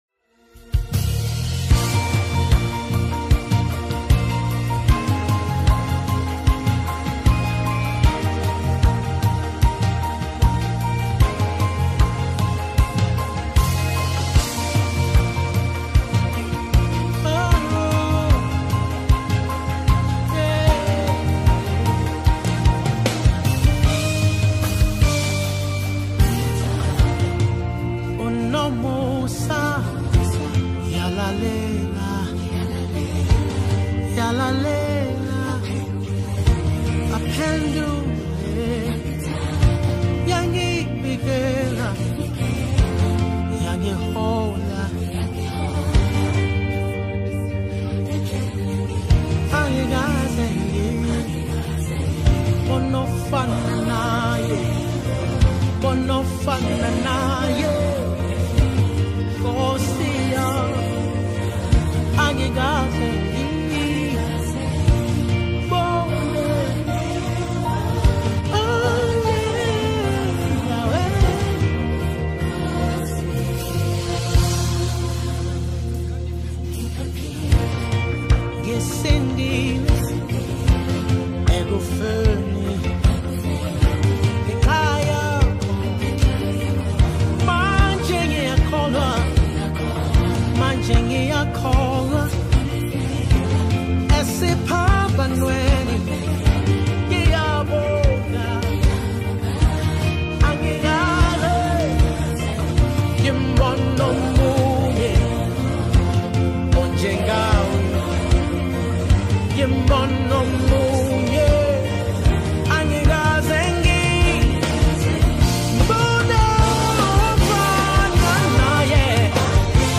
Mp3 Gospel Songs
praise and Worship